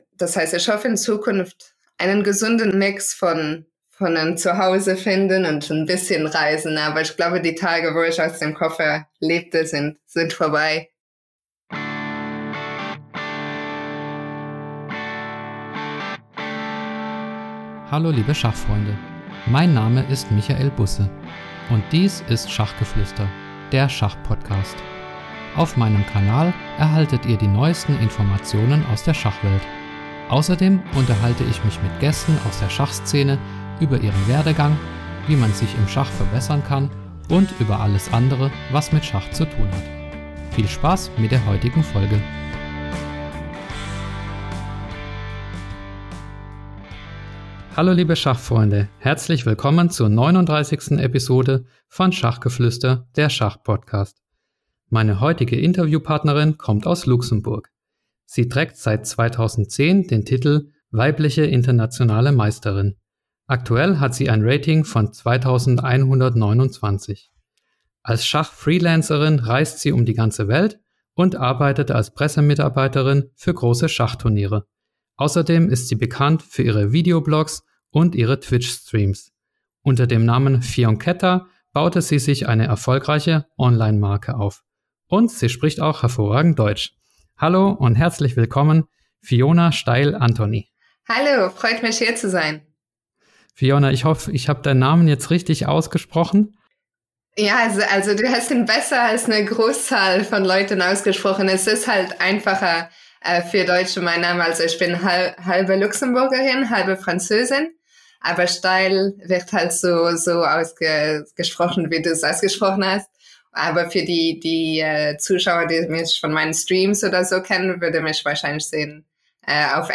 Ein Interview mit einer sympathischen Frohnatur.